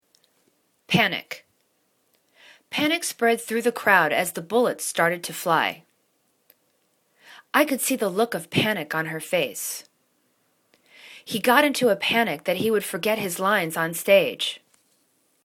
pan.ic    /'panik/    n